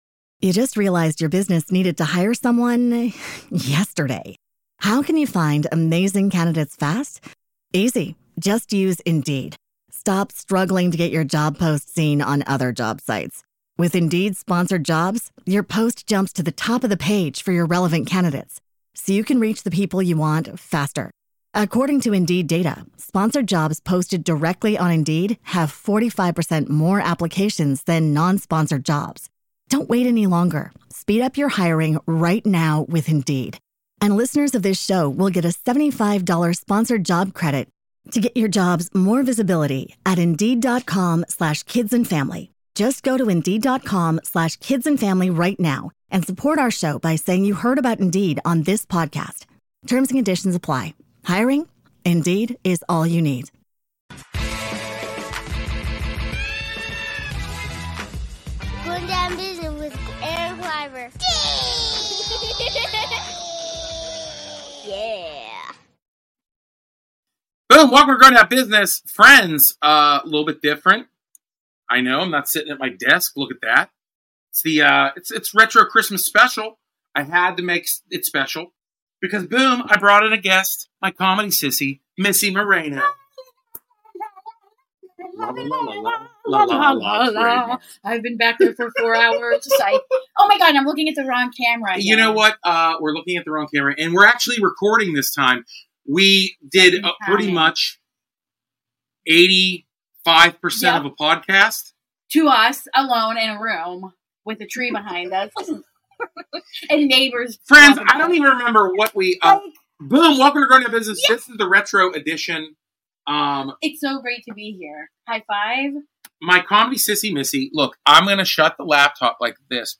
It’s comedy, it’s a podcast, it’s a vibe.